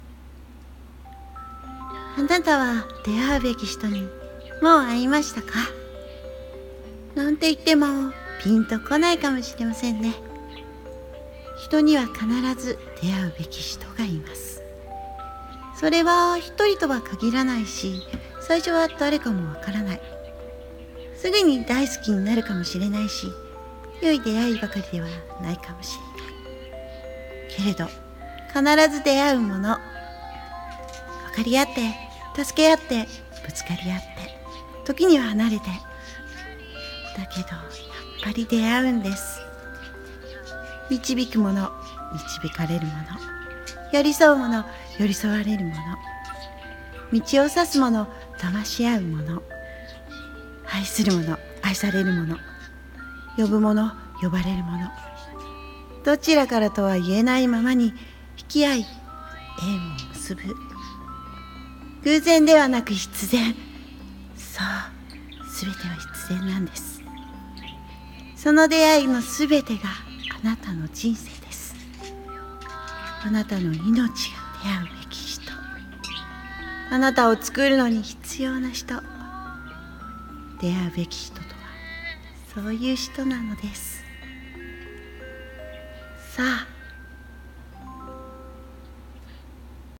】1人用声劇台本「出逢うべき人｣ 演者